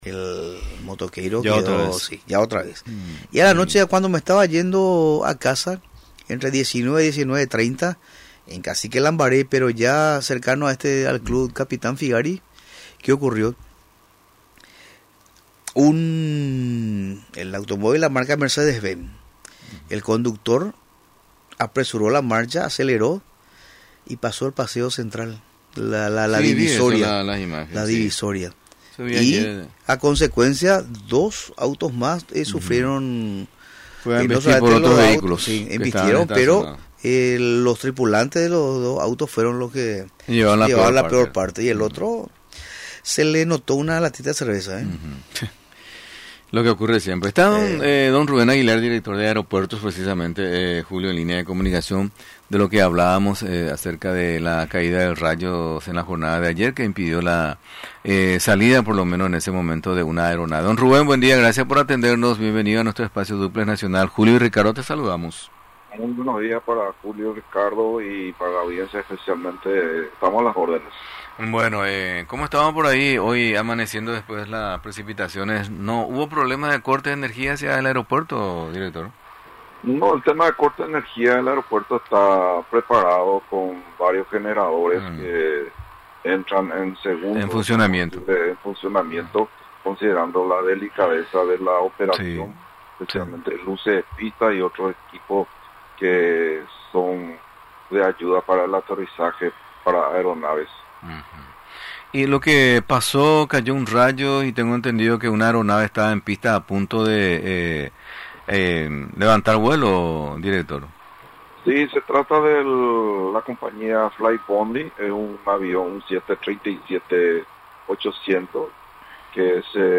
Durante la entrevista en Radio Nacional del Paraguay, explicó que de manera inmediata los mecánicos de aviación, verificaron si existió algún desperfecto.